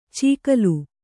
♪ cīkalu